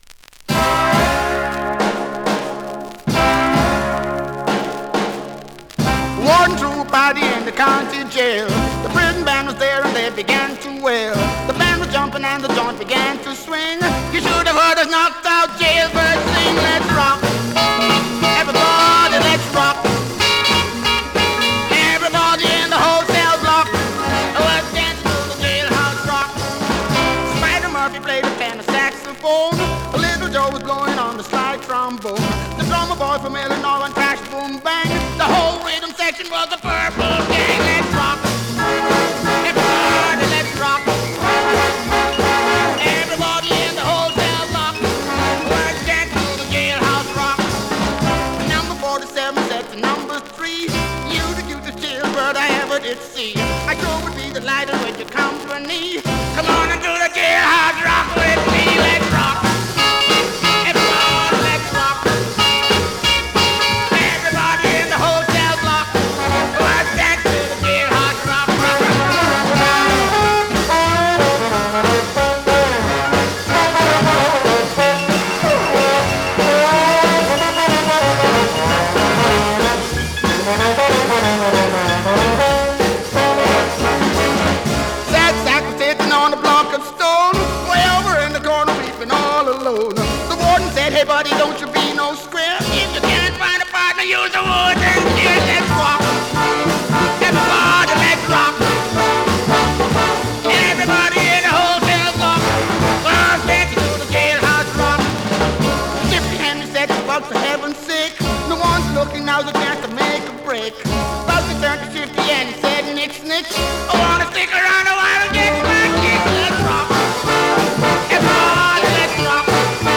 sung by a guy with a bad case of rabies